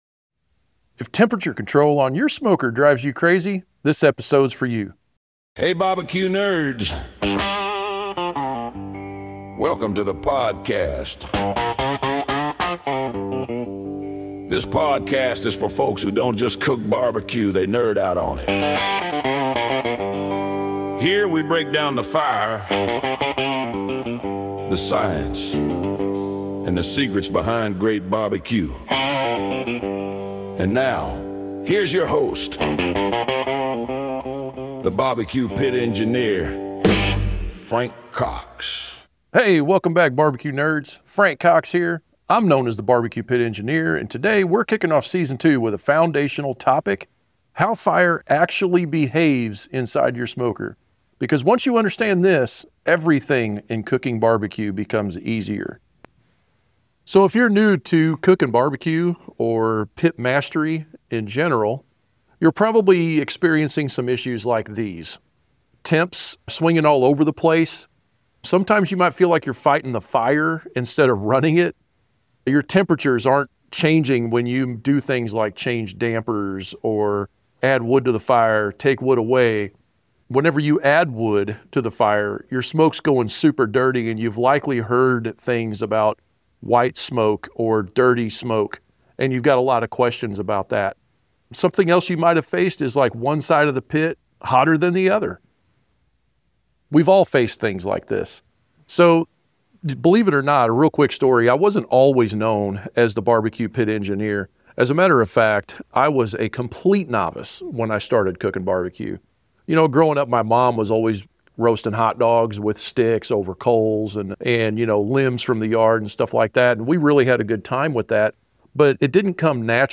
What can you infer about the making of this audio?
A clear breakdown of how fire really works inside a smoker. Learn the three types of heat, how airflow and draft actually function, and what causes temp swings. No interviews, no chatter — just real BBQ education for the true BBQ Nerds.